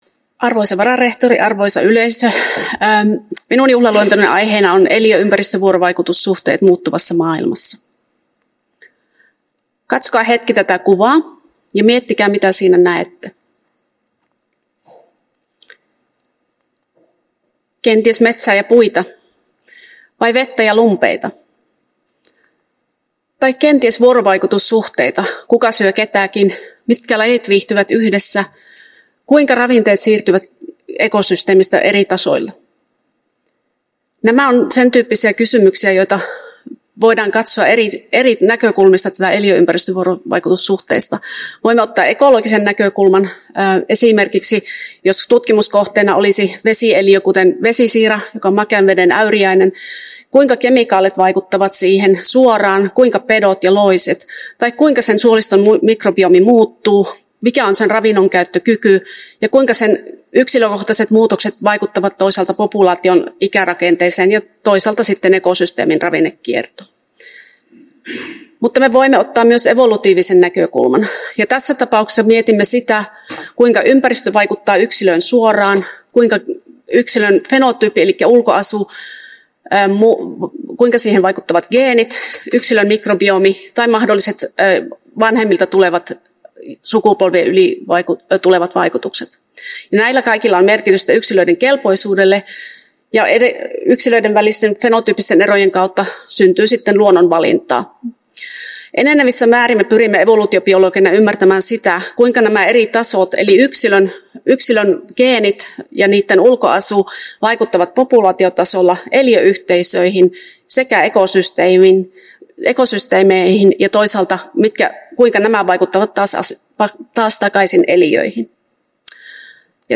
Uusien professoreiden juhlaluennot 10.12.2024